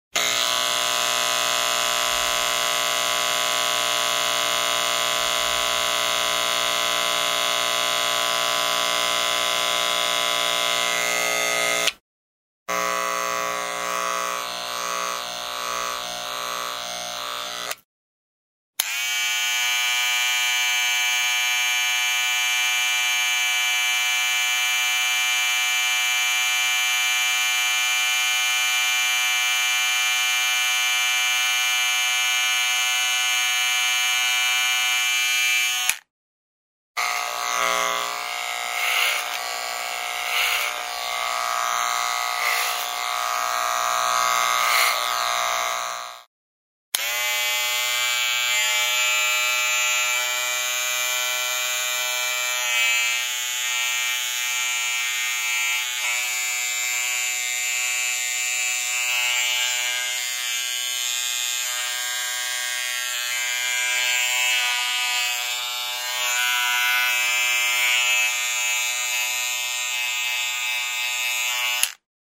Звуки парикмахерской
Стрижка волос машинкой для волос